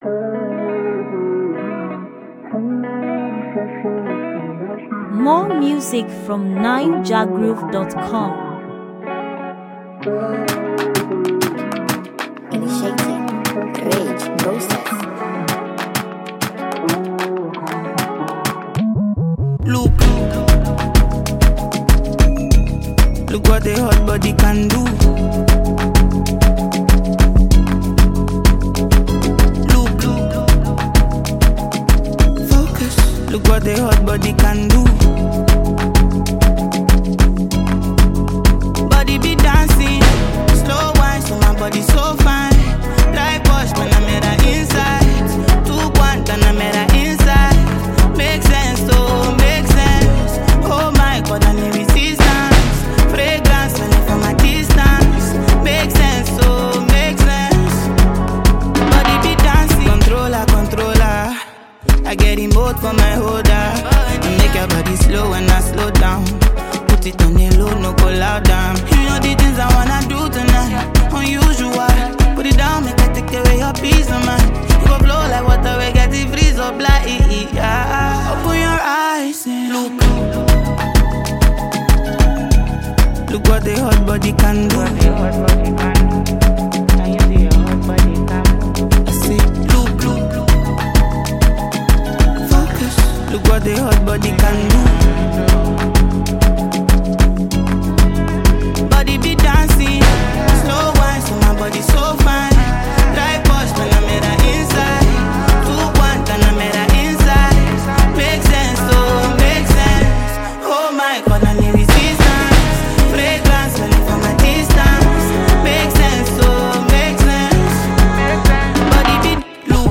Naija-music